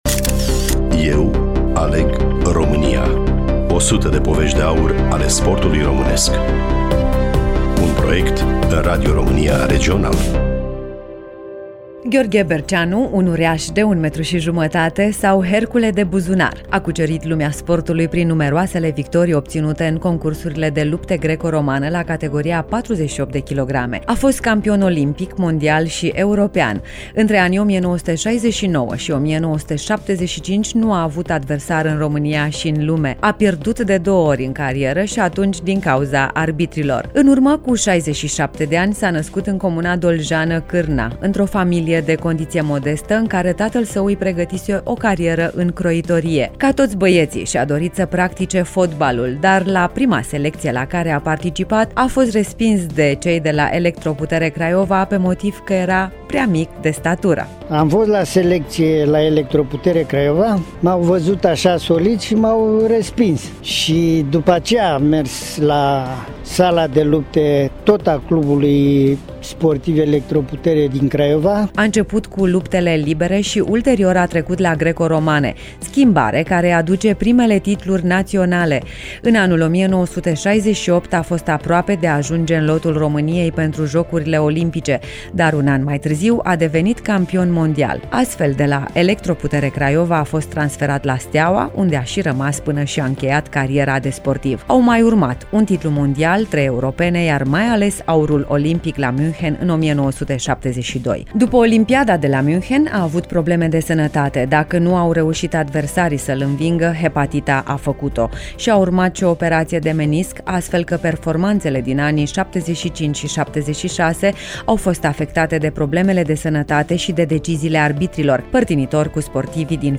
Gheorghe Berceanu îşi aminteşte atmosfera de la cea mai importantă competiţie a lumii:
Studioul Radio România Reşiţa